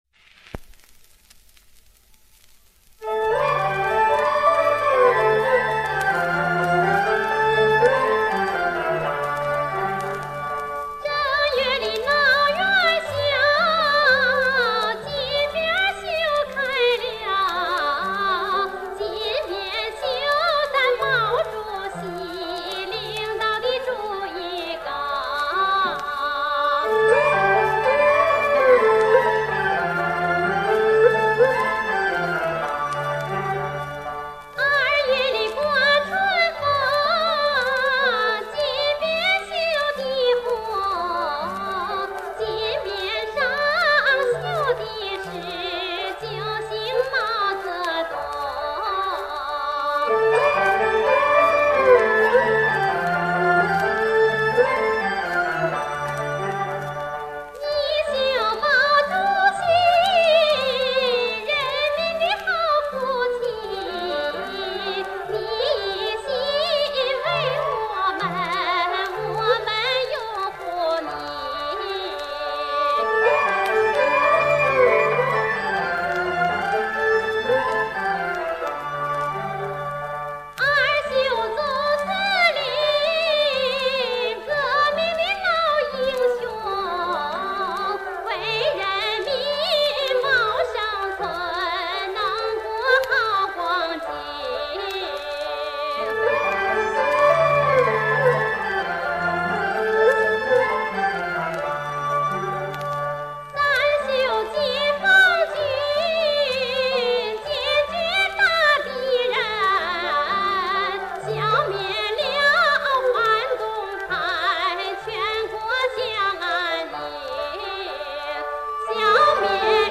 [25/12/2021]女高音歌唱家邓玉华60年代演唱的陕北民歌《绣金匾》